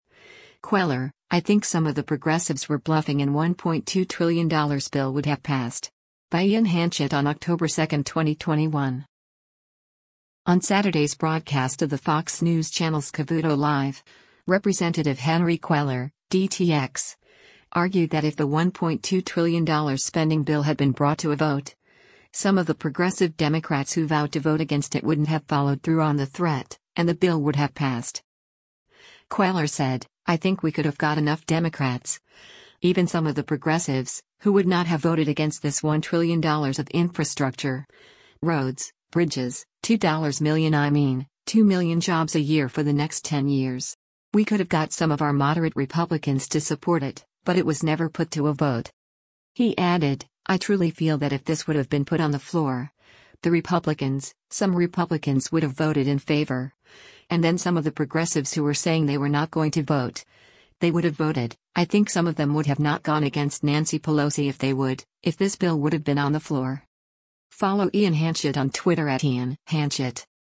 On Saturday’s broadcast of the Fox News Channel’s “Cavuto Live,” Rep. Henry Cuellar (D-TX) argued that if the $1.2 trillion spending bill had been brought to a vote, some of the progressive Democrats who vowed to vote against it wouldn’t have followed through on the threat, and the bill would have passed.